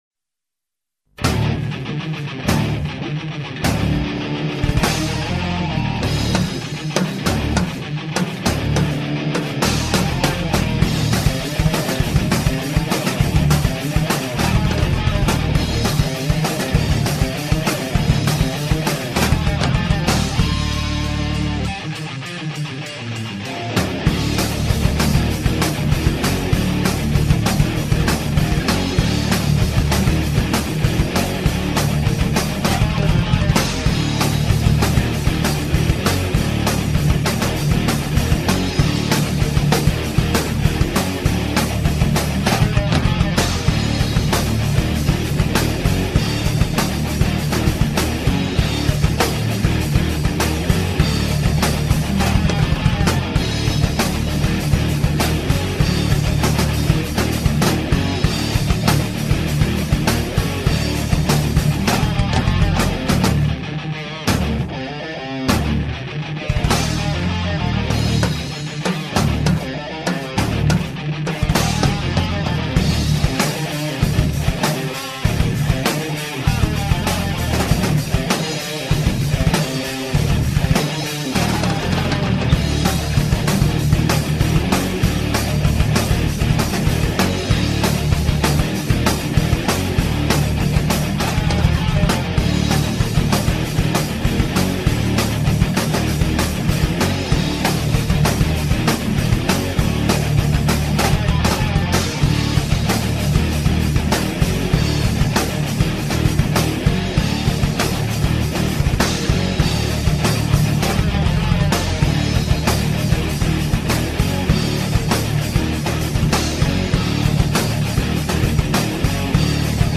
Metalband
Gitarre
Drum
Bass